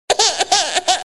哭.MP3